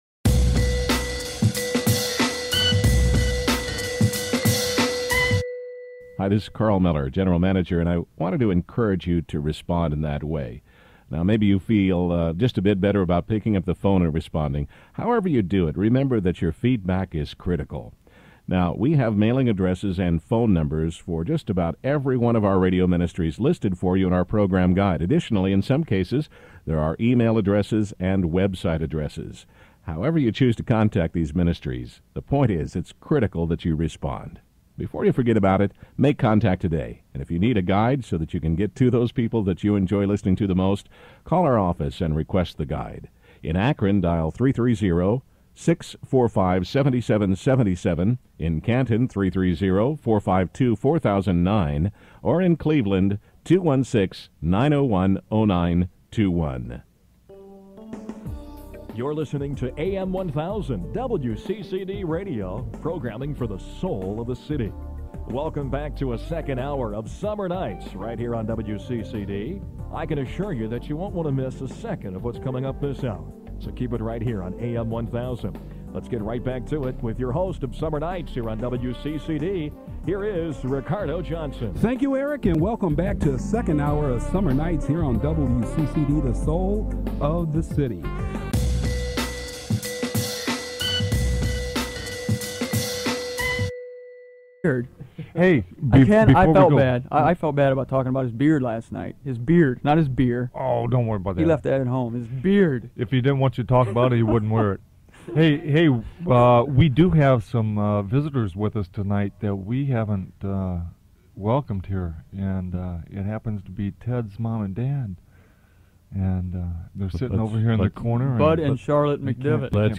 This is Part 3 (or Part C as the case may be) of a four-part series on the source. and purpose of evil. This is basic teaching, really.